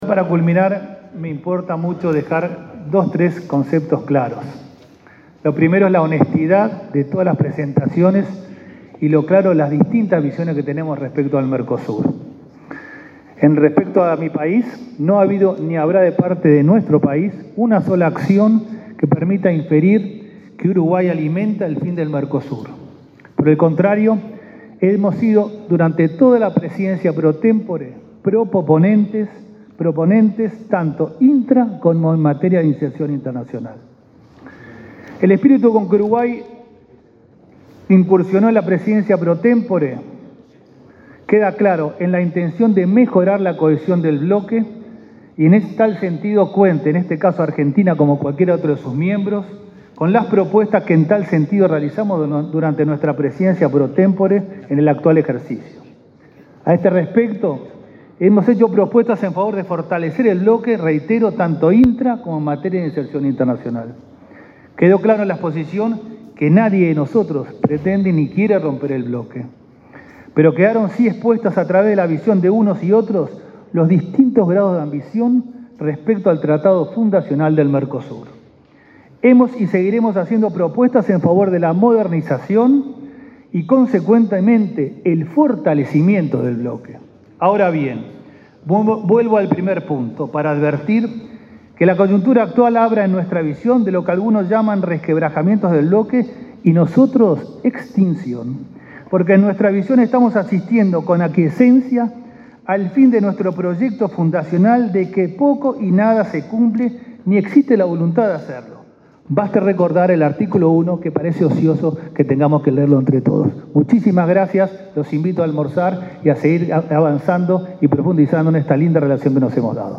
Palabras del canciller Francisco Bustillo
El canciller uruguayo Francisco Bustillo cerró, este lunes 5 en Montevideo, la LXI reunión ordinaria del Consejo del Mercado Común.